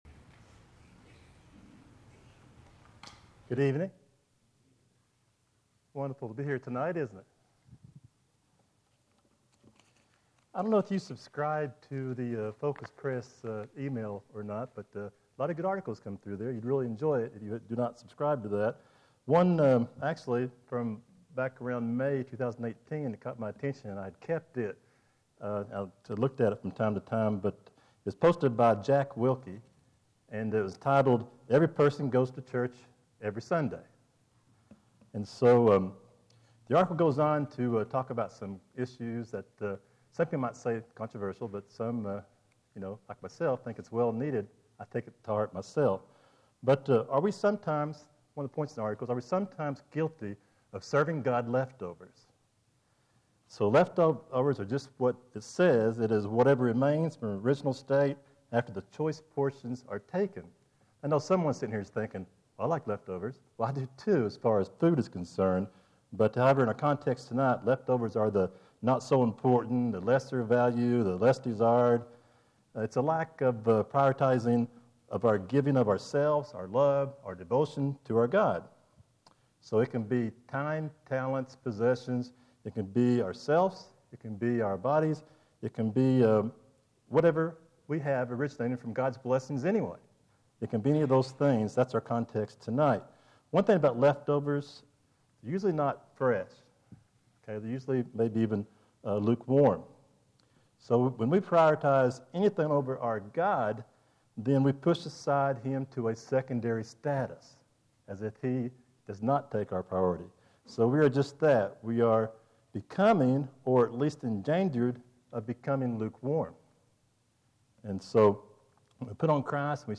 Filed Under: Featured, Lesson Audio